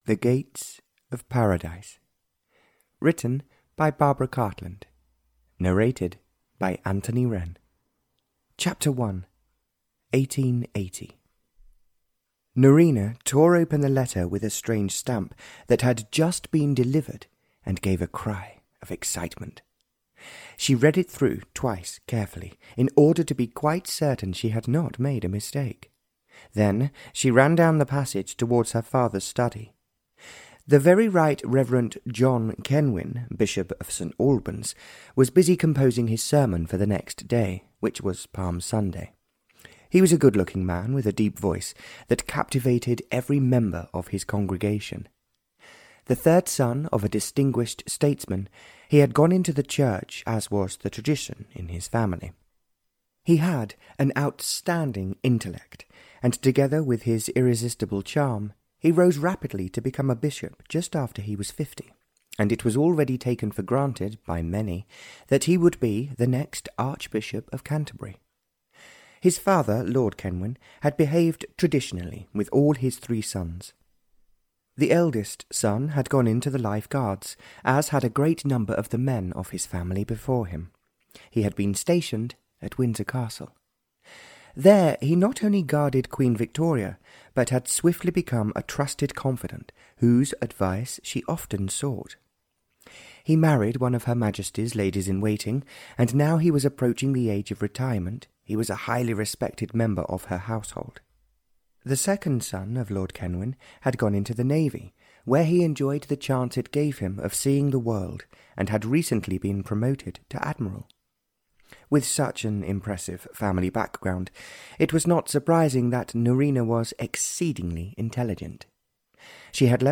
Audio knihaThe Gates of Paradise (Barbara Cartland s Pink Collection 77) (EN)
Ukázka z knihy